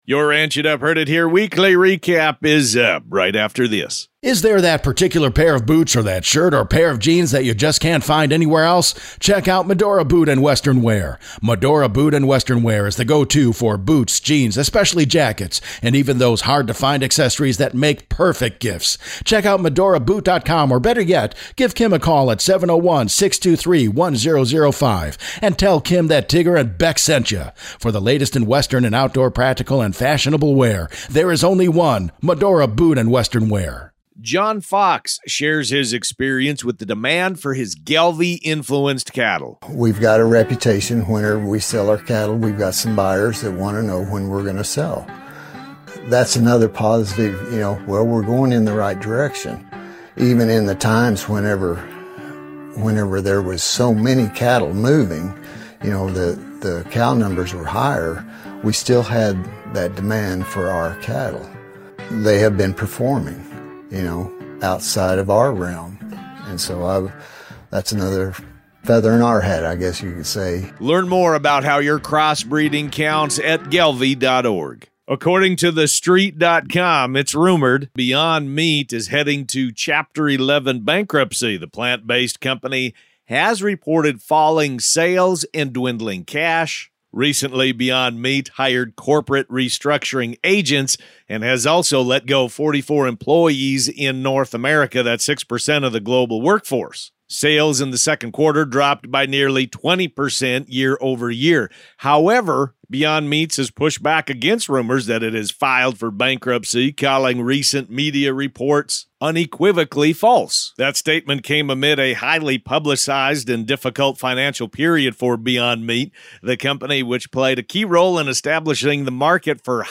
Welcome to this week's "Ranch It Up" radio show report!